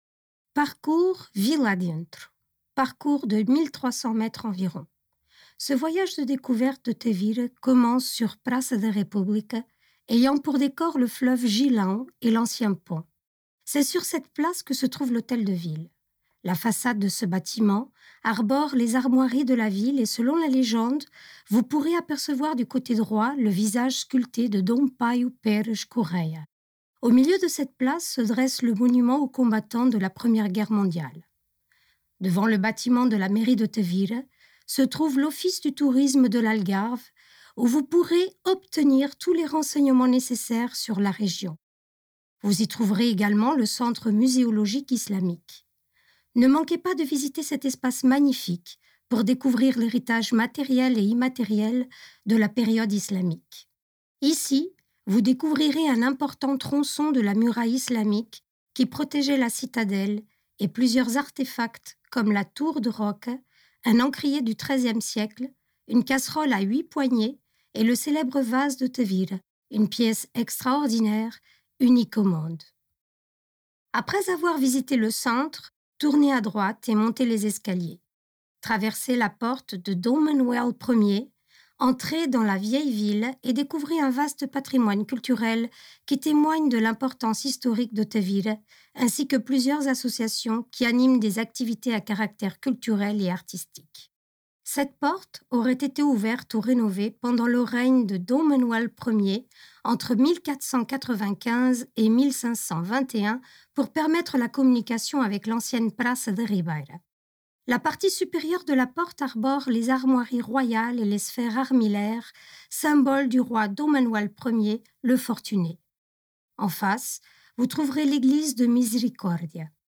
Distance 1.8km Durée moyenne 30-45 minutes Principaux points à visiter Paços do Concelho (hôtel de ville); Palácio da Galeria / Musée Municipal; Église Santa Maria do Castelo; Château de Tavira Guide audio_«Vila a dentro» «Vila a dentro»